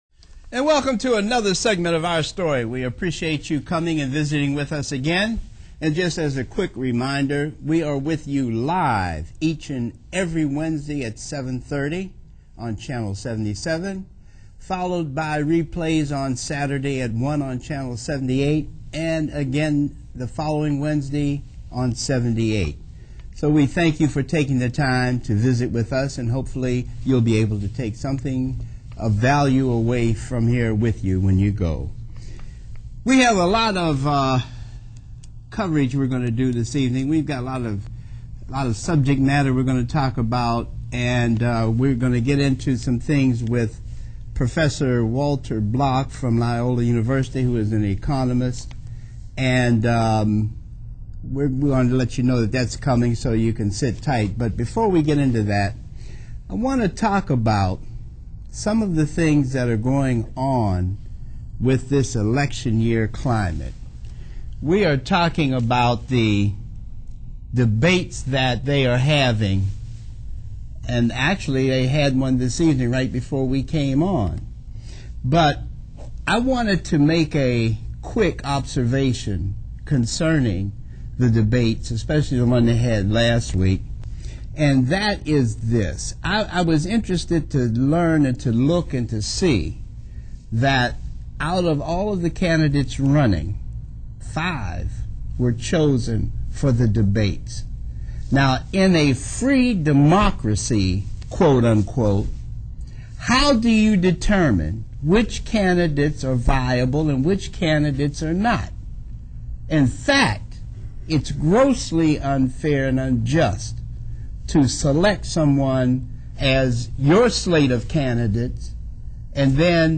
Recently Walter Block was on a TV show run by black power advocates. He thought they were going to discuss the Katrina hurricane disaster in New Orleans, but the discussion ended up focusing on racism and a variety of other issues.